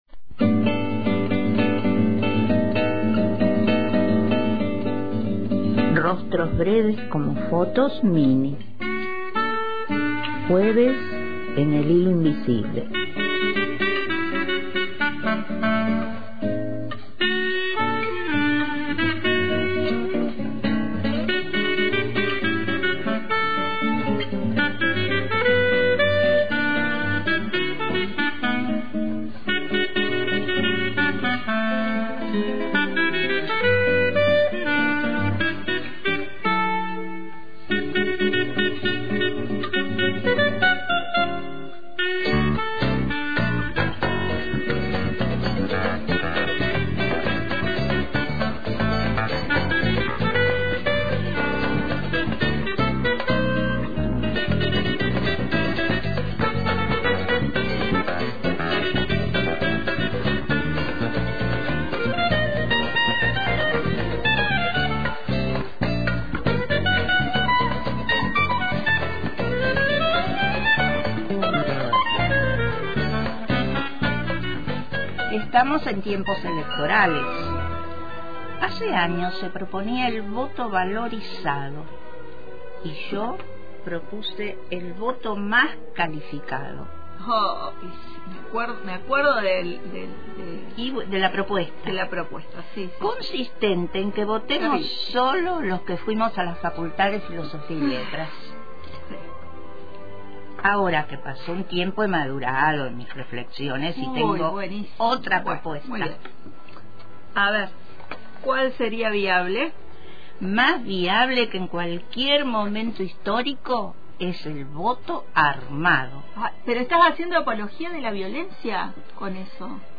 una encendida conversación radial en torno al “voto armado”